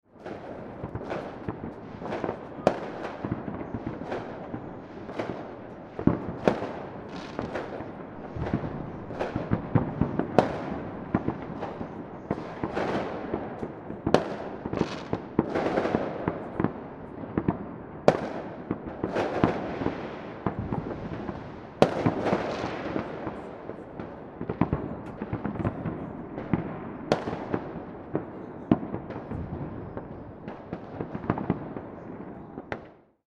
Street Fireworks For New Year Celebration Sound Effect
Street-fireworks-for-new-year-celebration-sound-effect.mp3